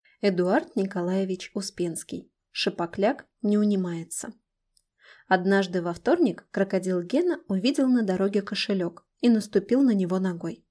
Аудиокнига Шапокляк не унимается | Библиотека аудиокниг